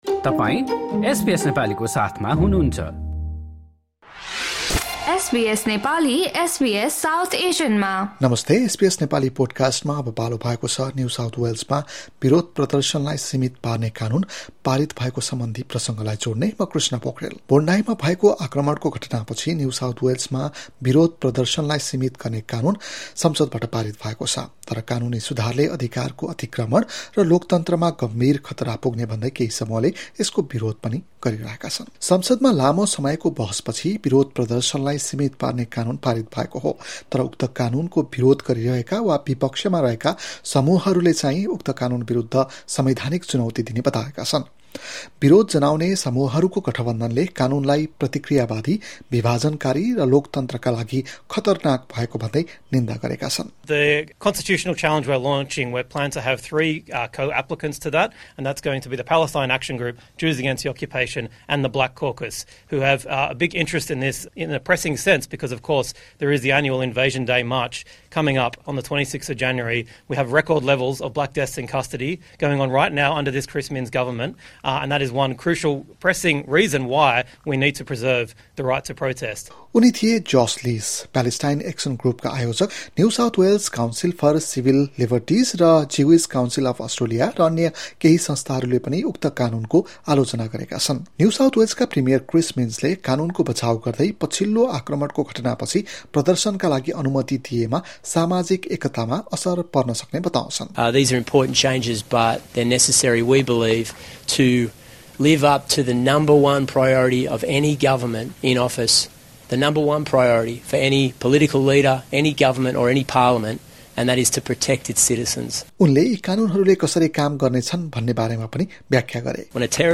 एक रिर्पोट।